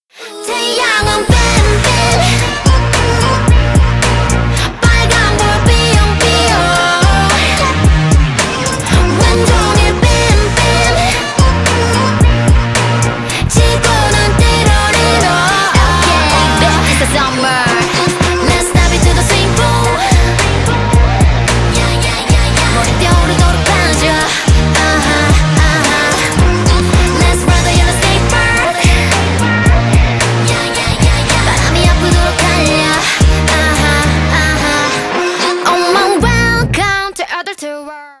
k-pop